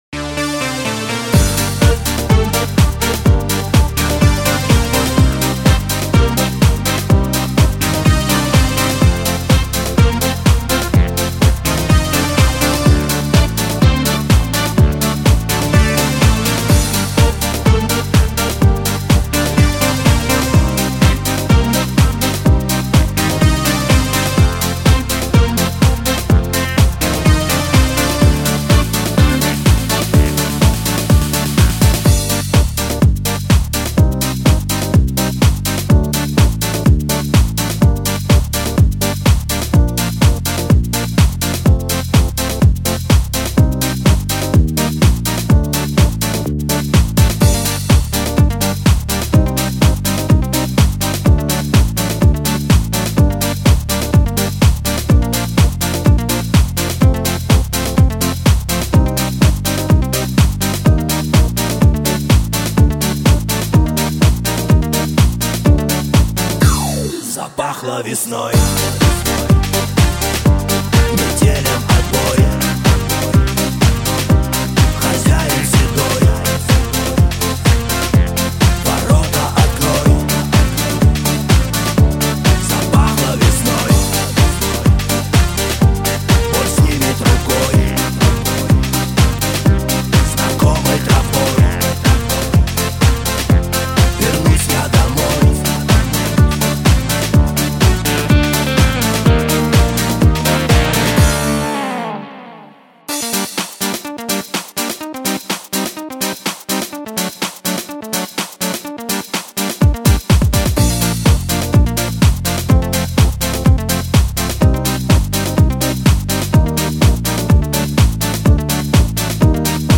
Часто используемые минусовки хорошего качества